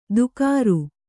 ♪ dukāru